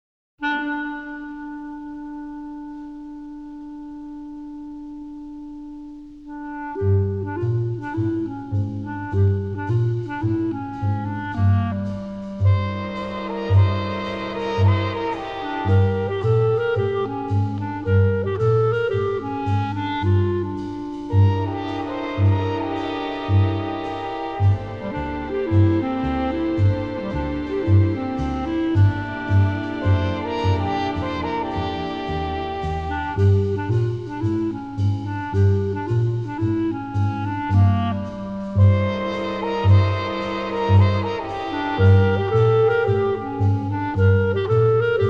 lovely, melodic scoring
newly remastered from the best possible stereo sources